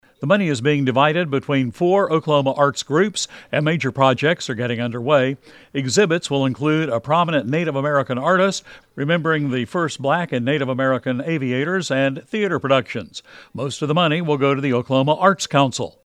CLICK HERE to listen to Radio Oklahoma's